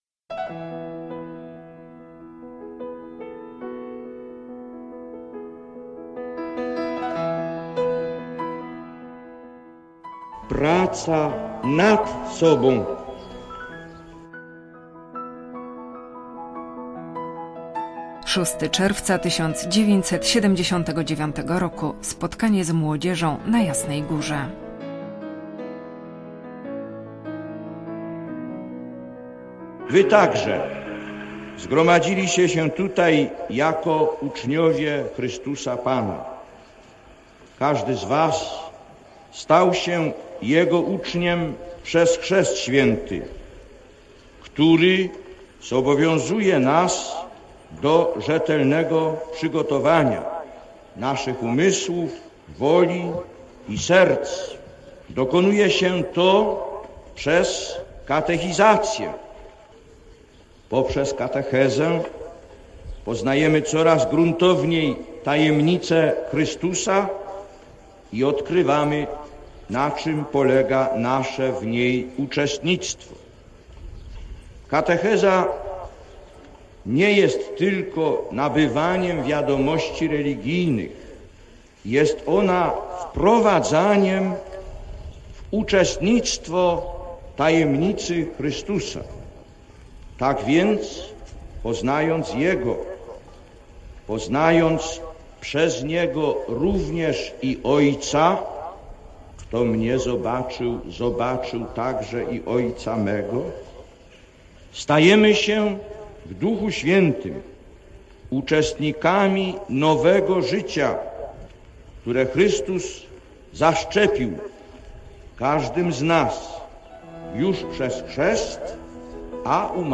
Słowo św. Jana Pawła II – Spotkanie z młodzieżą na Jasnej Górze (06.06.1979 r.)
Przemowienie.mp3